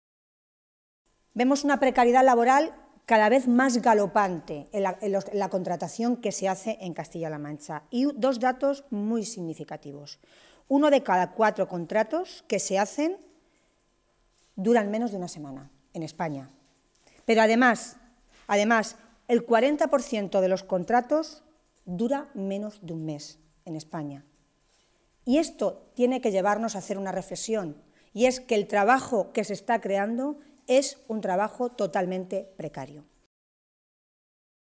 Tolón se pronunciaba de esta manera esta mañana, en Toledo, en una comparecencia ante los medios de comunicación en la que valoraba esa EPA del último trimestre del año 2014.
Cortes de audio de la rueda de prensa